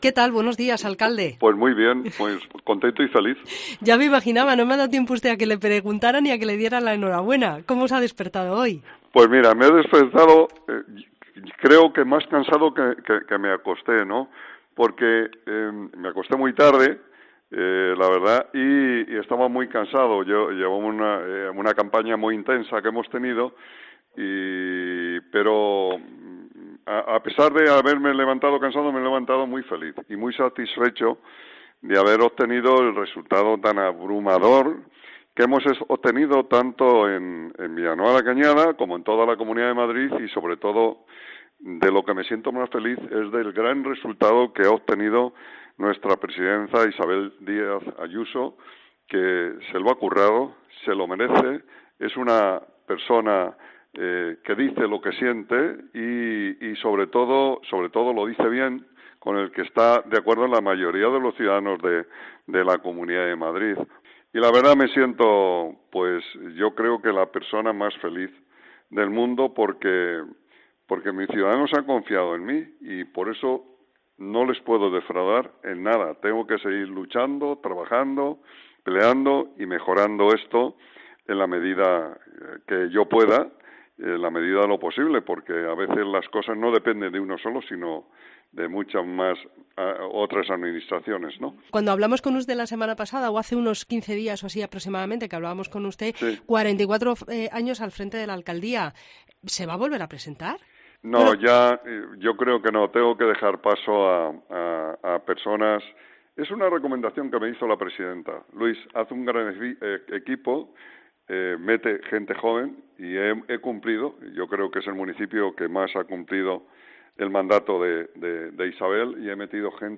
Atiende la llamada de COPE Madrid en el Ayuntamiento: “Estoy muy contento y feliz.